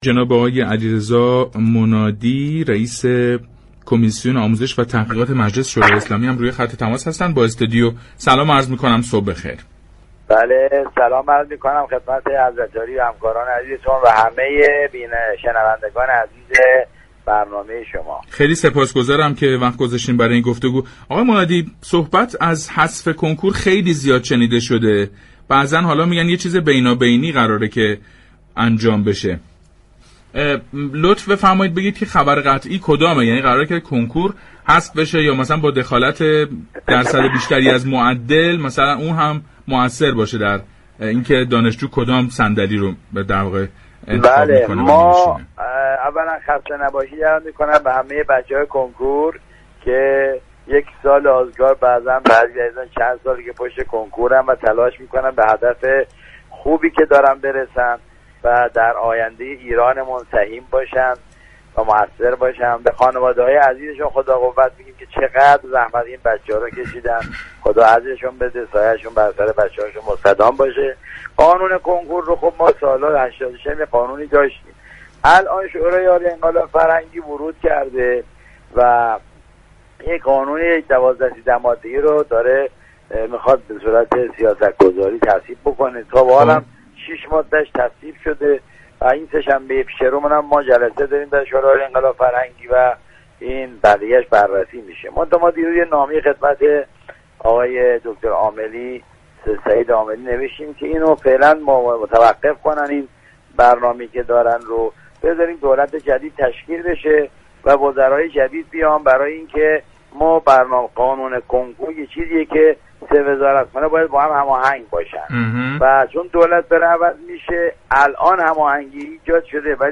رئیس كمیسیون آموزش و تحقیقات مجلس یازدهم در گفتگو با برنامه پارك شهر 13 تیرماه گفت: كنكور فعلا حذف‌شدنی نیست اما با قانون جدید، دانش‌آموزان دوبار كنكور می‌دهند و حداقل آن این است كه استرس آنها كاهش می‌یابد.